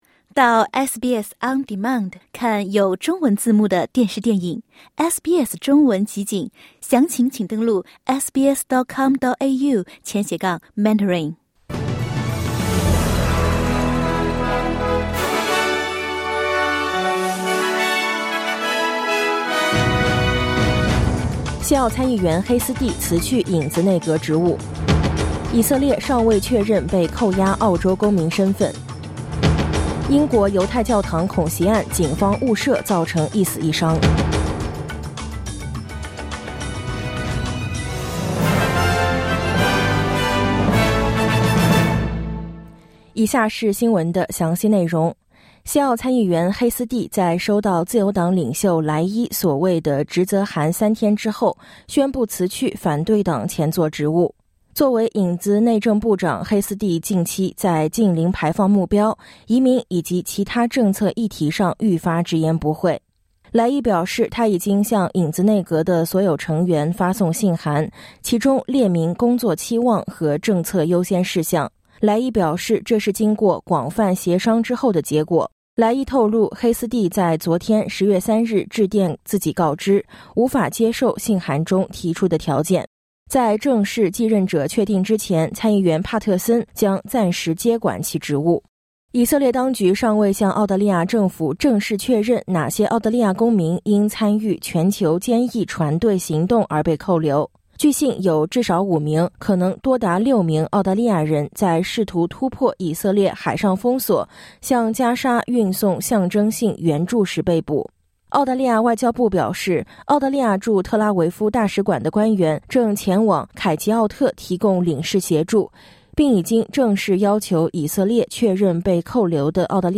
SBS早新闻（2025年10月4日）
西澳参议员黑斯蒂辞去影子内阁职务；以色列尚未确认被扣押澳洲公民身份；英国犹太教堂恐袭案警方误射造成一死一伤。点击音频，收听完整报道。